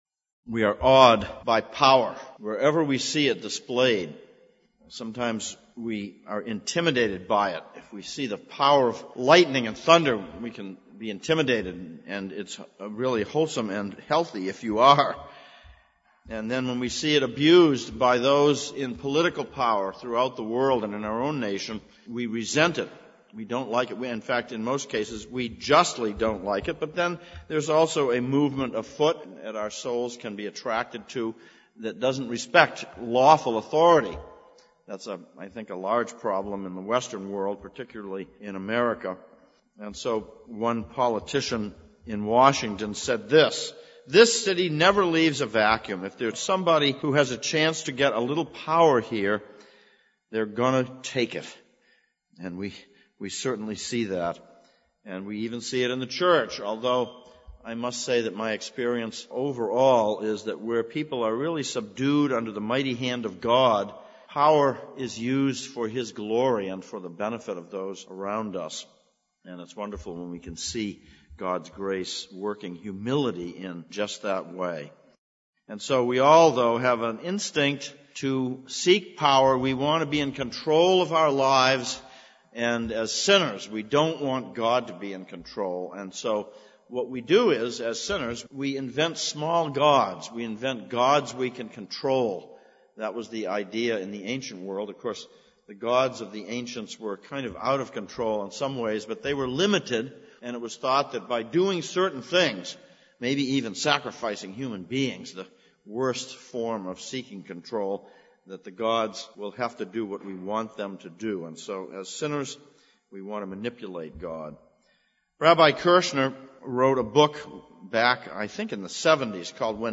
Ephesians 1:15-23 Service Type: Sunday Evening «